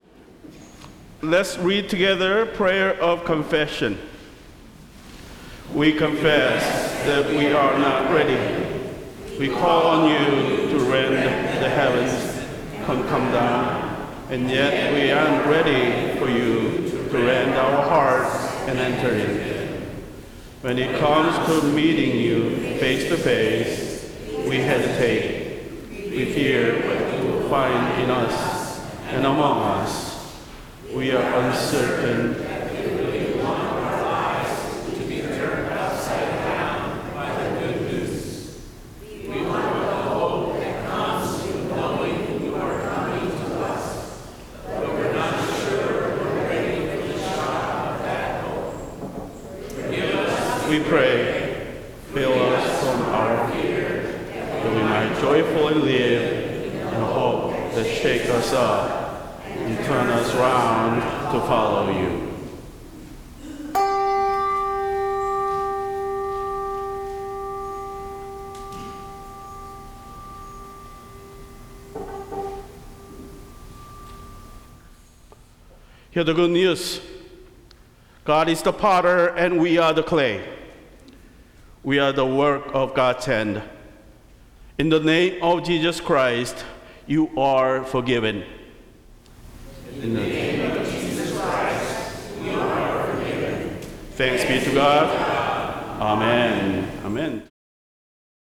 Service of Worship
Prayer of Confession